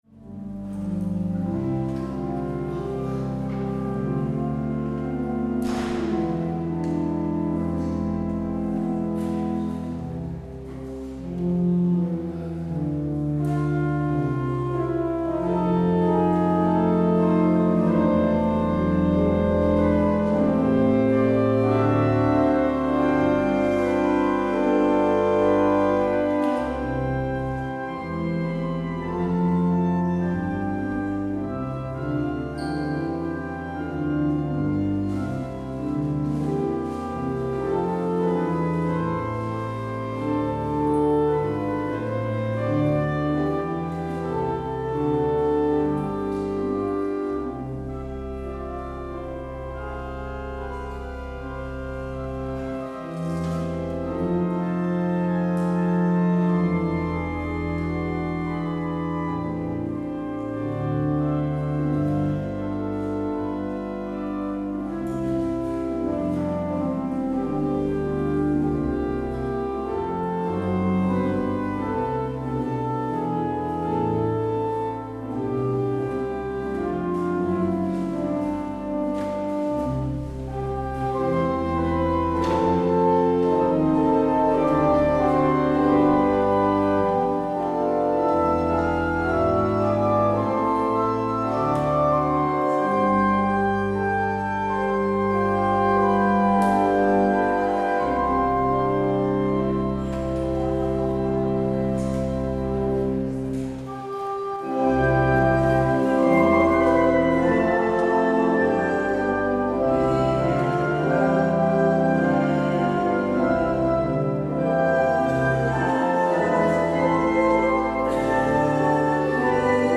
 Luister deze kerkdienst terug: Alle-Dag-Kerk 30 augustus 2022 Alle-Dag-Kerk https